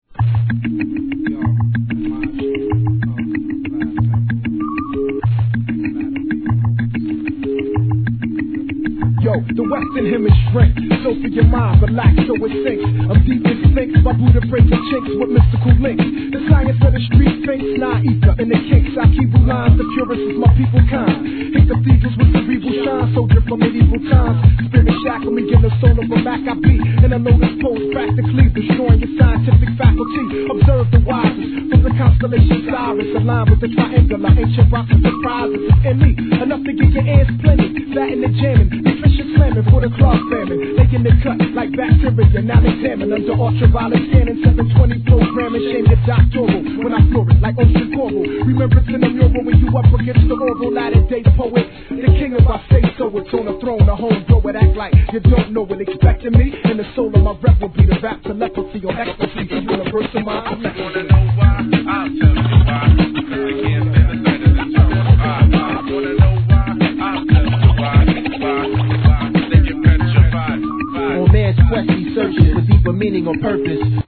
HIP HOP/R&B
フィラデルフィア産アンダーグランド!